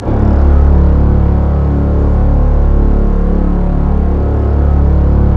Index of /90_sSampleCDs/Roland - String Master Series/CMB_Combos 2/CMB_Ominousness
STR STRING00.wav